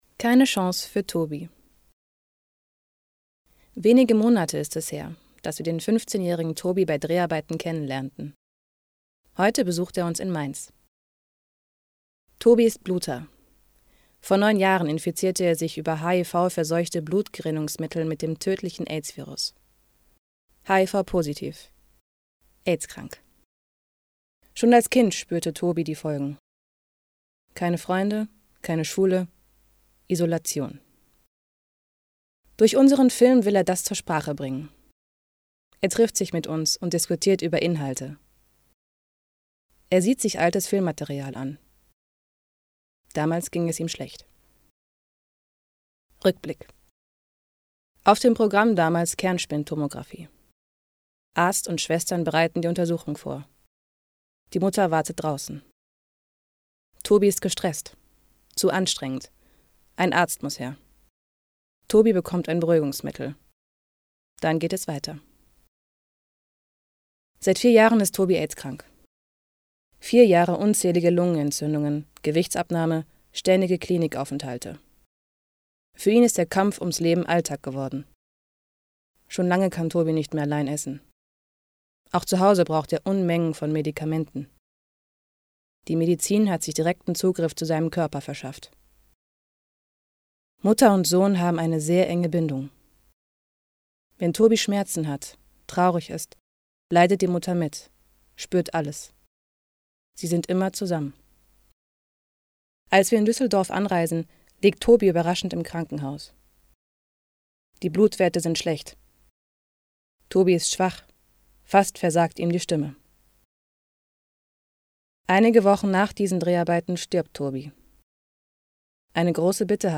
Junge Sprecherin deutsch
Sprechprobe: Industrie (Muttersprache):
Young German voice